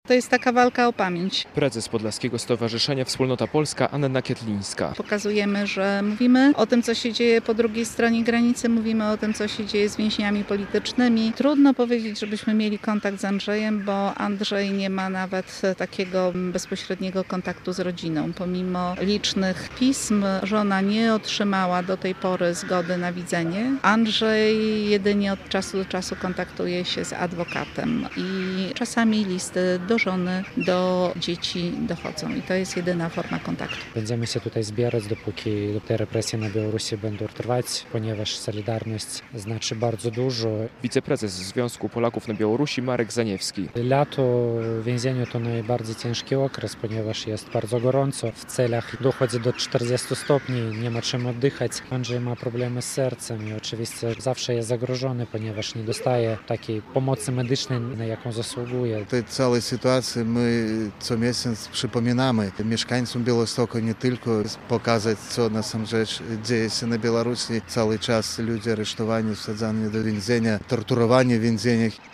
Pikieta w obronie Andrzeja Poczobuta - relacja